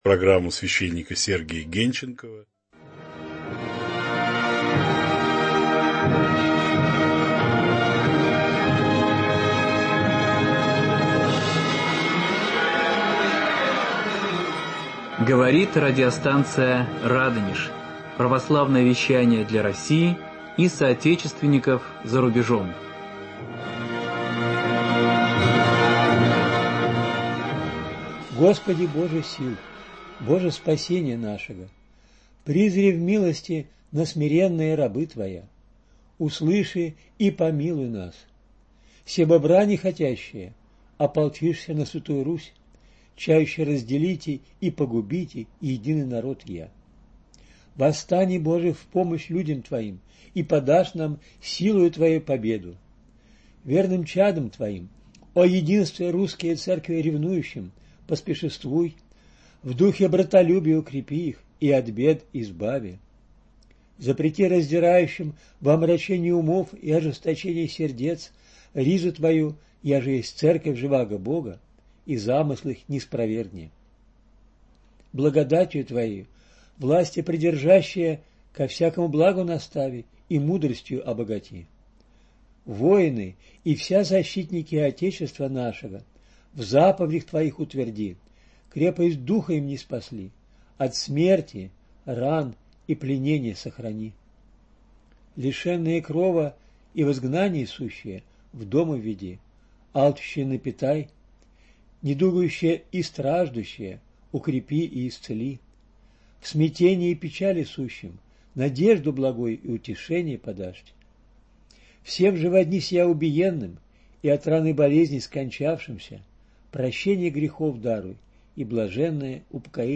19.05.2025 22:02 Слушать Скачать MP3-архив часа Сорок шестая беседа. Разбор послания апостола Павла. Пятая глава второго послания к коринфянам.